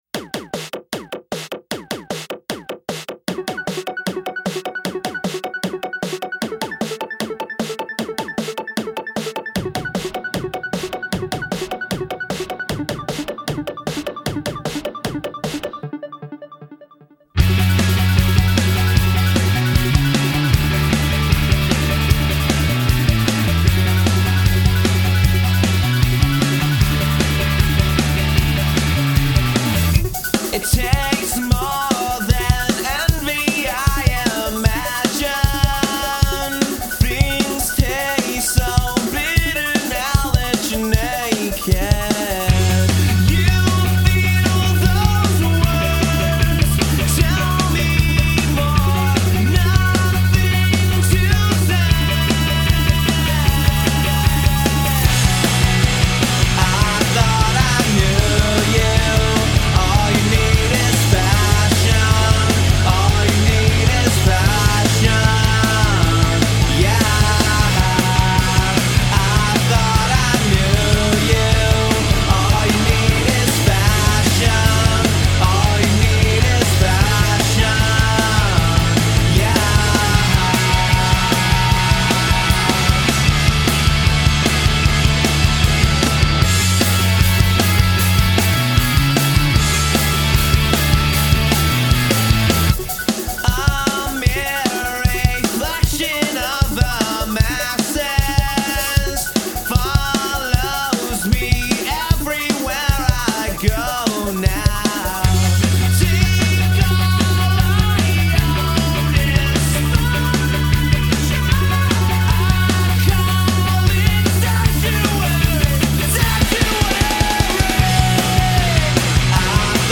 When it came to Vancouver post-hardcore or post-punk…